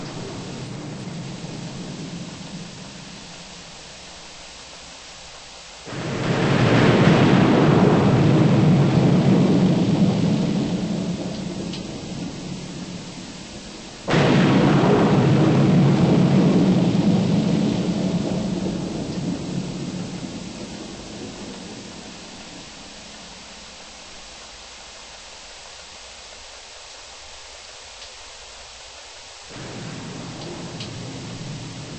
10 Minutes Of Thunderstorm   B1 10 Minutes Of Waves   B2 Artificial Winds   B3 Dripping Water 　　盤質：良好　ジャケ：良好